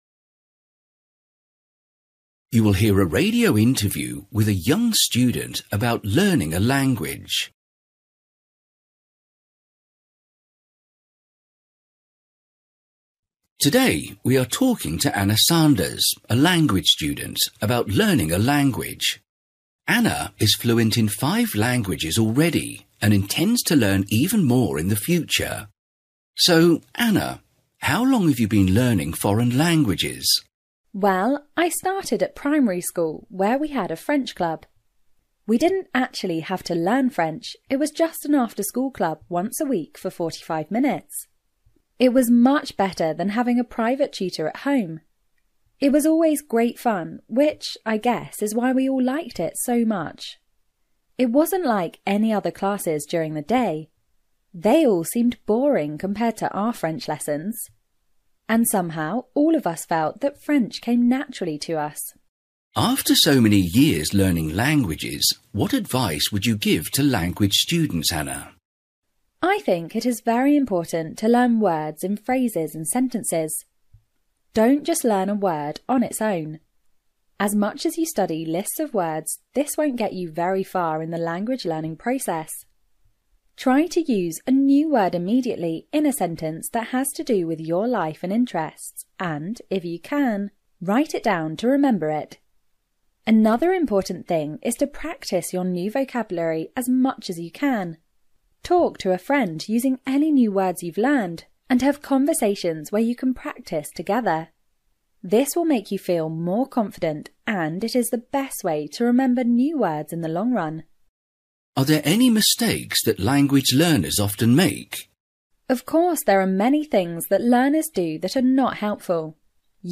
Listening: interview with a young student about learning a language
You will hear a radio interview with a young student about learning a language.